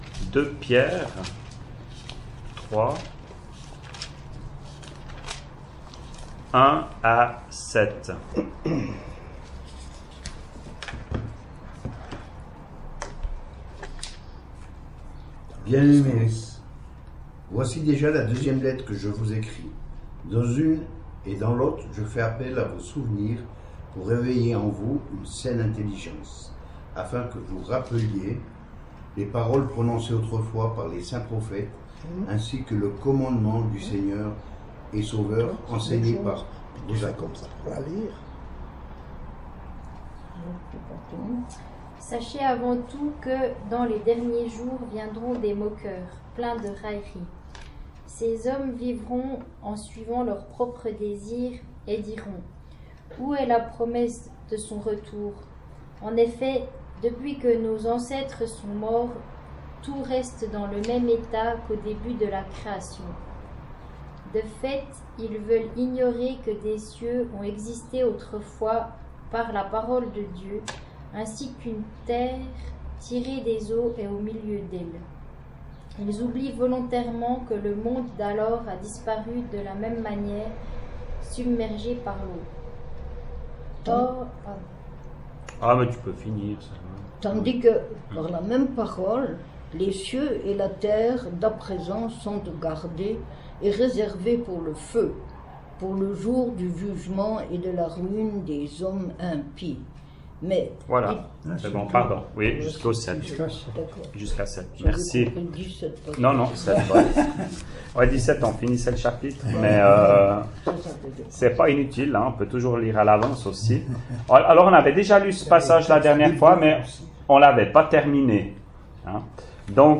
[Chapelle de l’Espoir] - Étude biblique : Deuxième Épître de Pierre, 9ième partie
ÉTUDE BIBLIQUE : Evole, le 26.06.2019